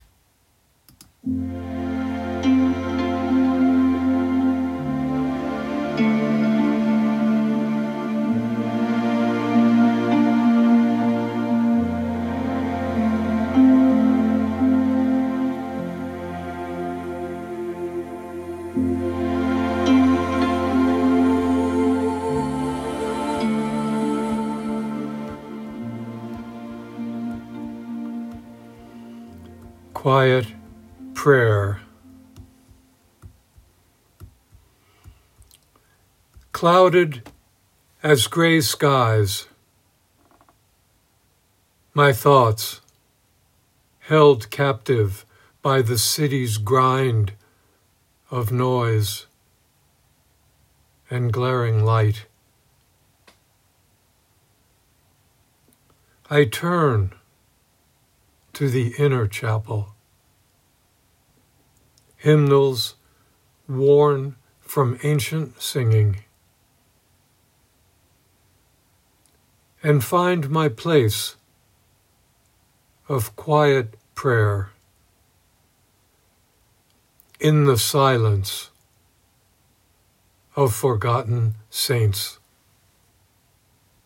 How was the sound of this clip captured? Reading of “Quiet Prayer” with music by Enya.